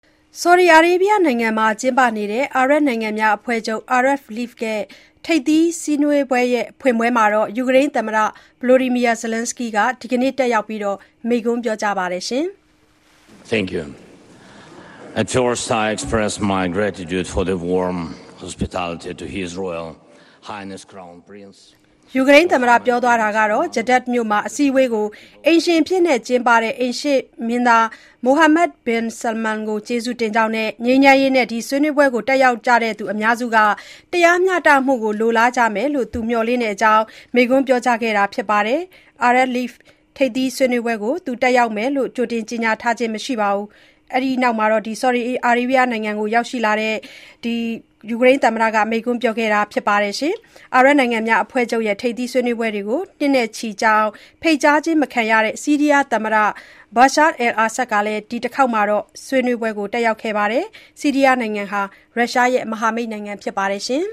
အာရပ်အဖွဲ့ချုပ်ထိပ်သီးဆွေးနွေးပွဲမှာ ယူကရိန်းသမ္မတ မိန့်ခွန်းပြော
ဆော်ဒီအာရေဗျနိုင်ငံမှာ ကျင်းပနေတဲ့ အာရပ်နိုင်ငံများအဖွဲ့ချုပ် Arab League ထိပ်သီးစည်းဝေးပွဲဖွင့်ပွဲမှာ ယူကရိန်း သမ္မတ Volodymyr Zelenskyy ဒီနေ့ (မေ ၁၉) တက်ရောက် မိန့်ခွန်းပြောကြားပါတယ်။